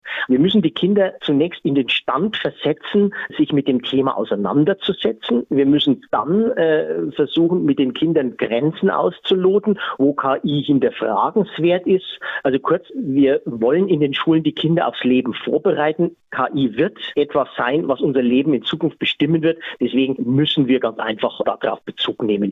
Interview: Künstliche Intelligenz an Schulen - PRIMATON